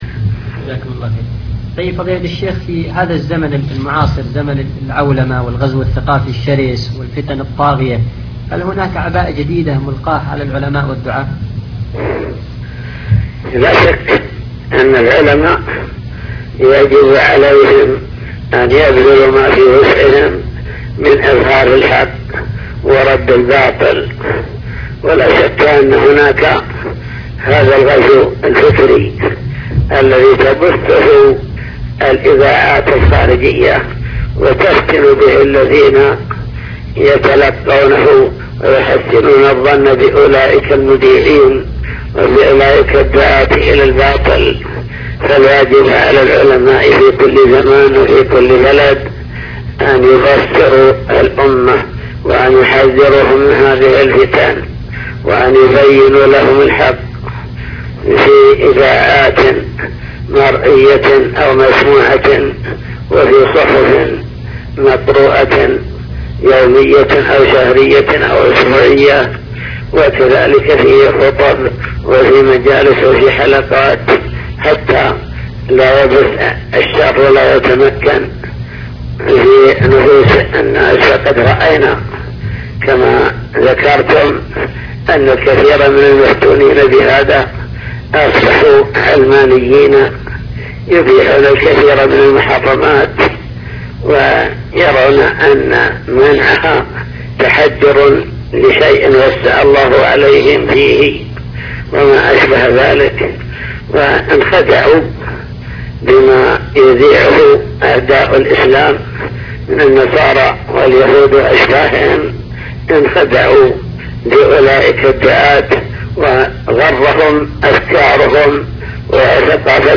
تسجيلات - لقاءات